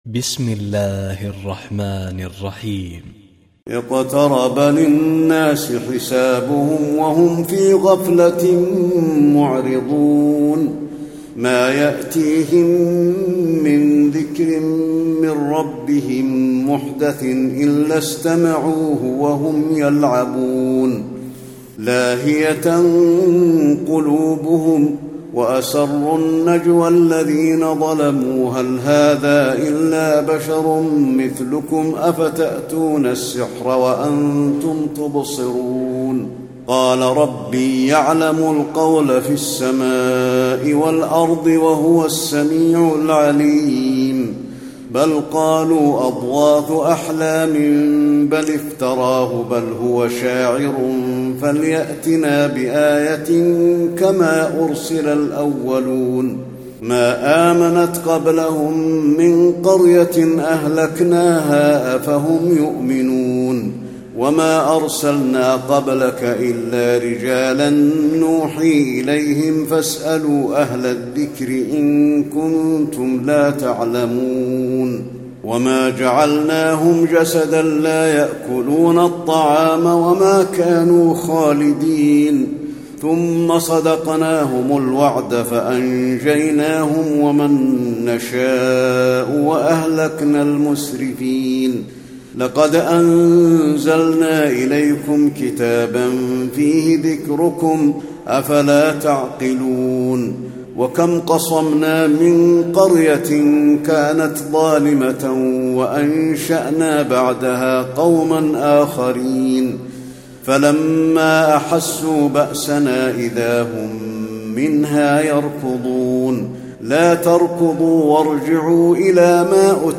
تراويح الليلة السادسة عشر رمضان 1435هـ سورة الأنبياء كاملة Taraweeh 16 st night Ramadan 1435H from Surah Al-Anbiyaa > تراويح الحرم النبوي عام 1435 🕌 > التراويح - تلاوات الحرمين